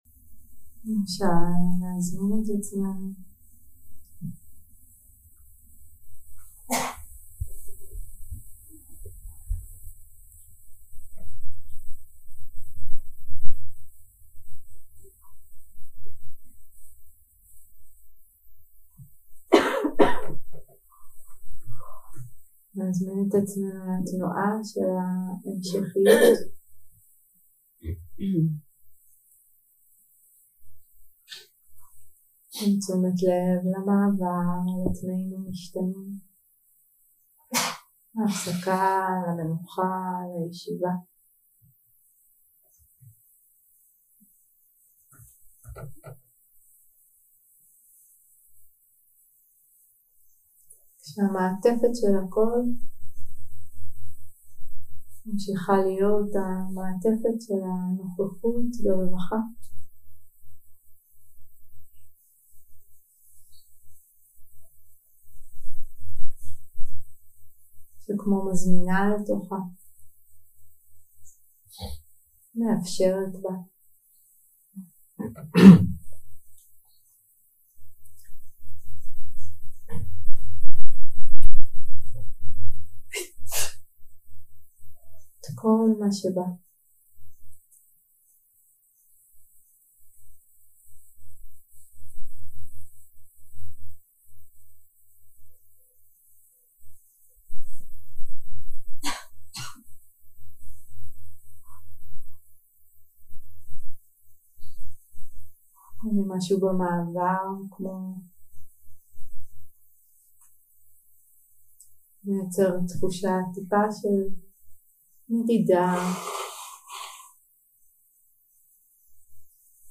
יום 5 – הקלטה 11 – צהריים – מדיטציה מונחית - ראייה שמטה לחופש 0:00 0:00 סוג ההקלטה: Dharma type: Guided meditation שפת ההקלטה: Dharma talk language: Hebrew